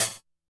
snare06.wav